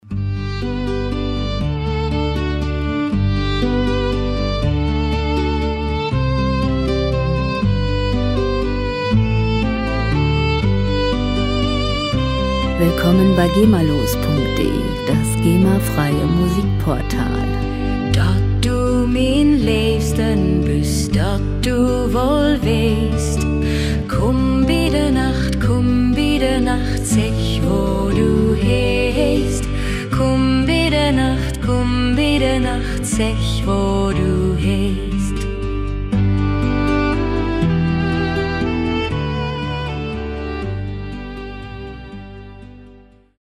Musikstil: Norddeutsche Volksmusik
Tempo: 120 bpm
Tonart: G-Dur
Charakter: heimatverbunden, ehrlich